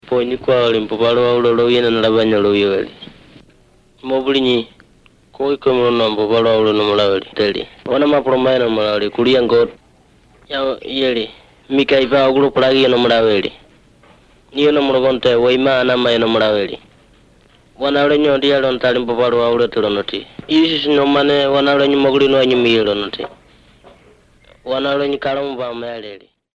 Words of Life recordings contain short Bible stories, evangelistic messages and songs.
Most use a storytelling approach. These are recorded by mother-tongue speakers